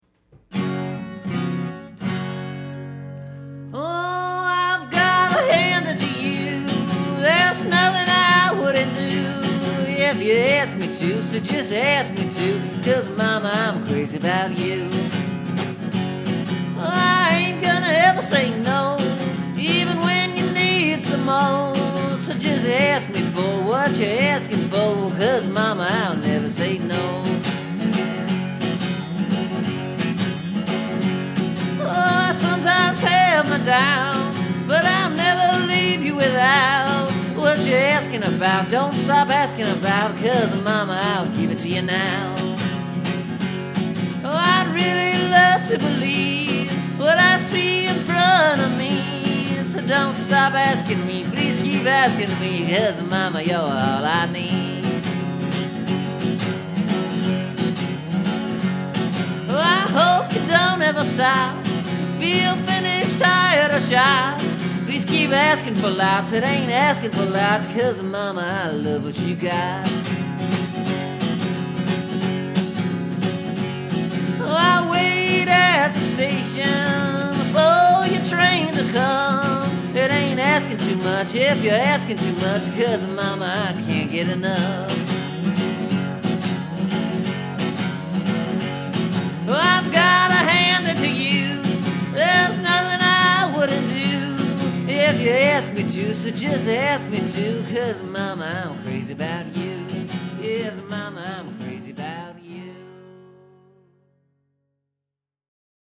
You’ll hear the repetition and the way I just sink right into a simple equation.